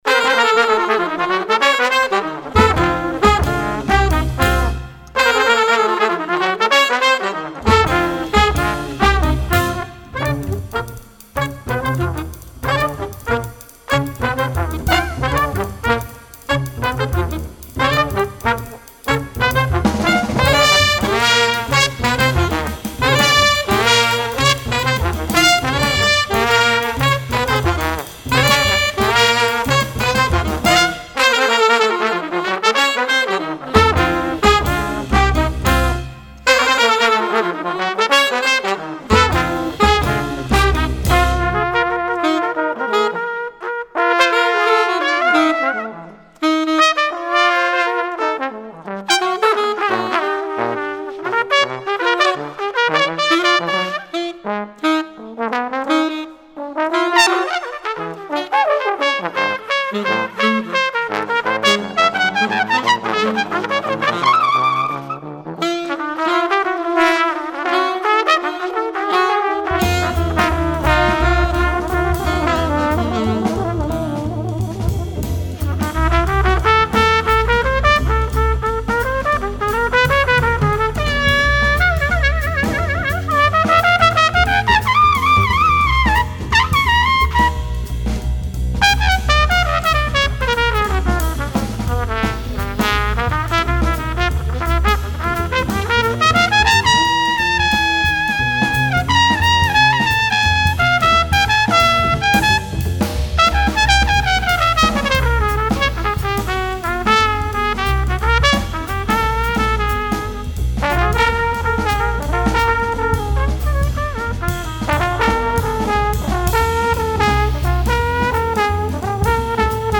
Il vous propose des interviews, de la musique pour un moment de partage et de convivialité avec les [...]